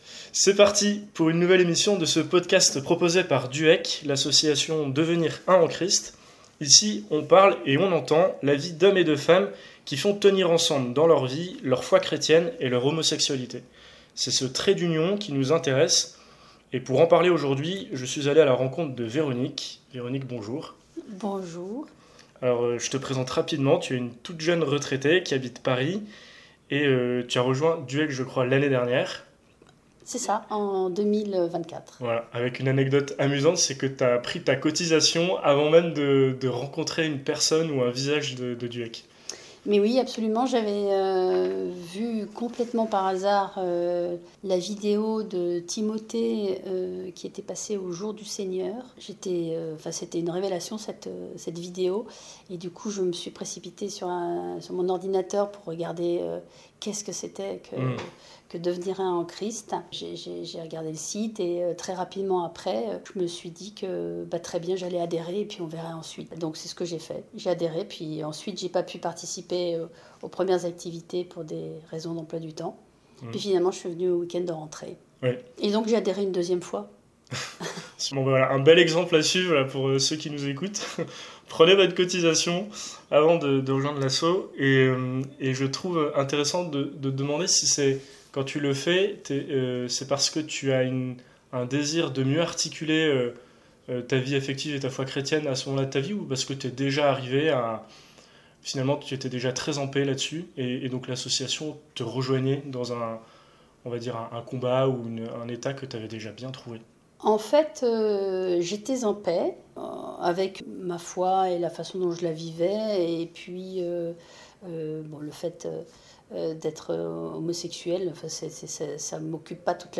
Trait d’Union, le podcast de DUEC Trait d’Union, un podcast proposé par l’association Devenir Un En Christ dans lequel on parle de foi et homosexualité. Vous y trouverez des témoignages de vie de personnes chrétiennes homosexuelles.